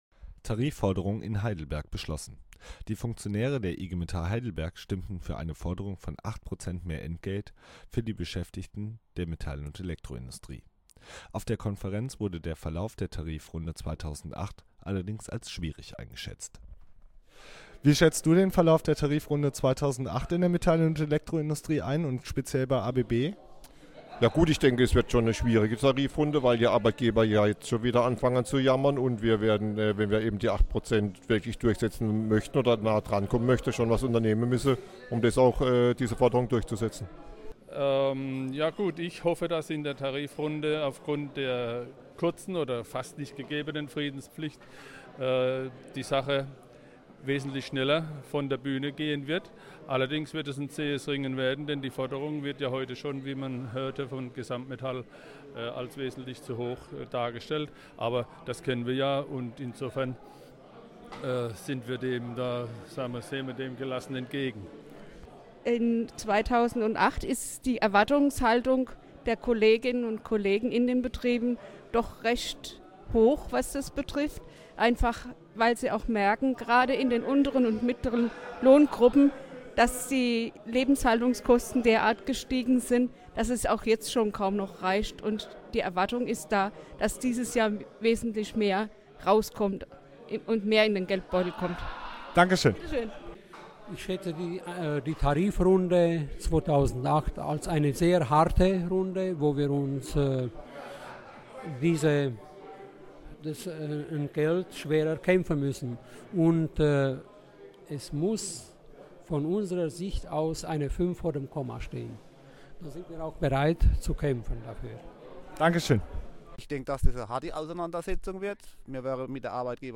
O-Töne zum erwarteten Verlauf der Tarifrunde 2008